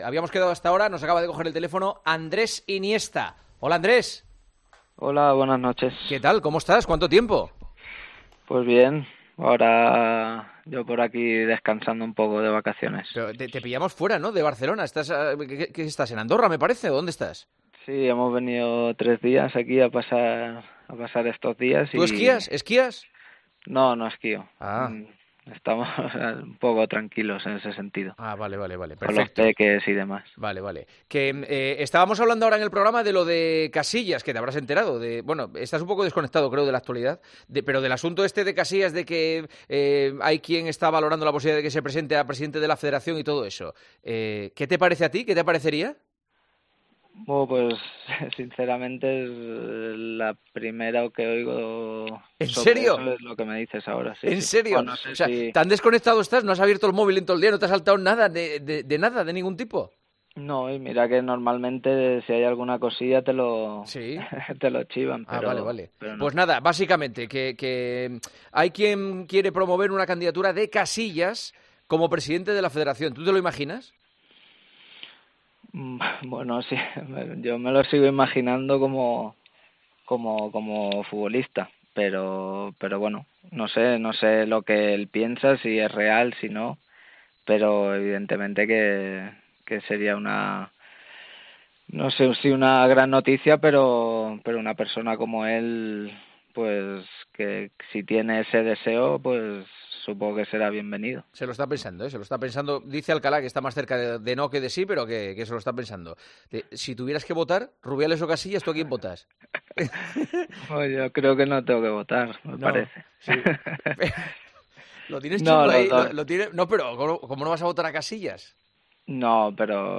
Aprovechando el descanso que estos días está haciendo Andrés Iniesta con su familia en Andorra, el jugador del Vissel Kobe japonés atendió este jueves la llamada de El Partidazo de COPE , con Juanma Castaño , donde se enteró de la noticia de que a Iker Casillas le han ofrecido entrar en la carrera por la presidencia de la Federación Española de Fútbol : "Es lo primero que oigo.